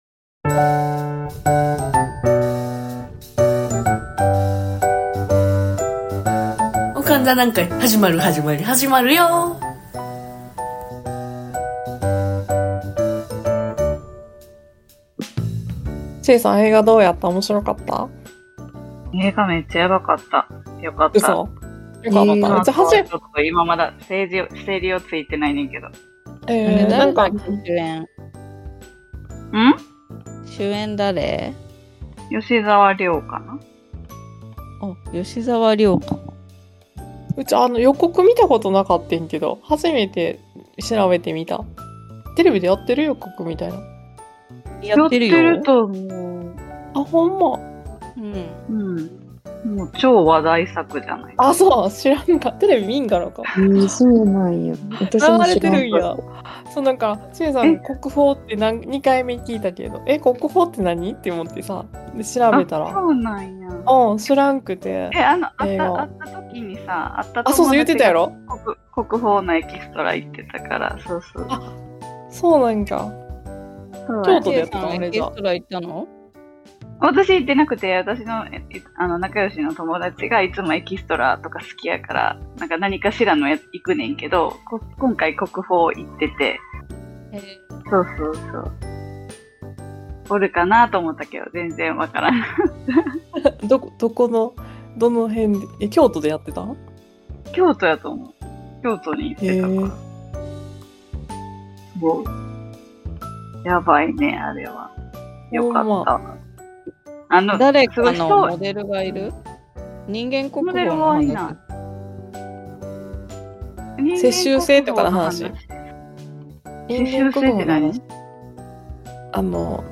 映画を観た感想を話しながらもオカン達の個性あふれるトーク炸裂⍥映画は1人でゆっくりみたい派？